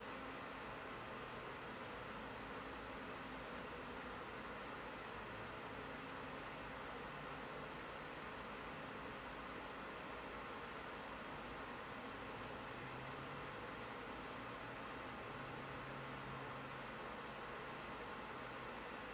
All clips were recorded under the same conditions, using an iPhone 16 Pro placed 3 feet away from the fan, with the fan running at full speed and blowing away from the microphone.